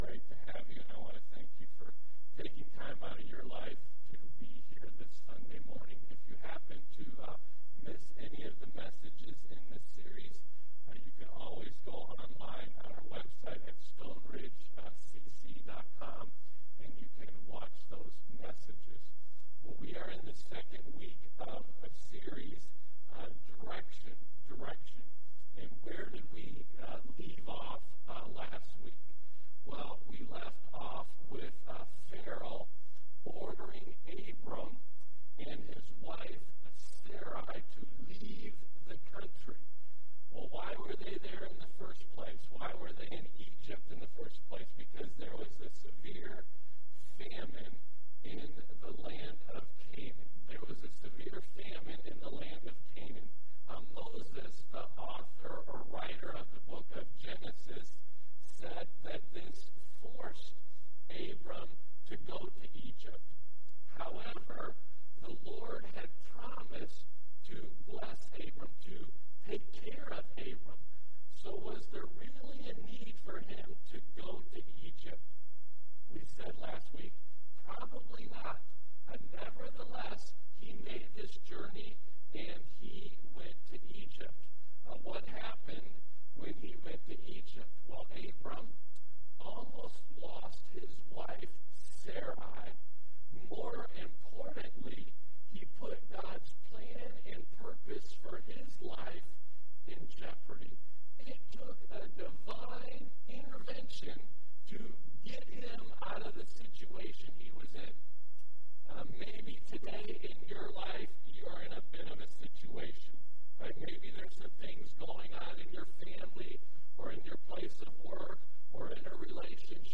Stone Ridge Community Church Sermon Audio Library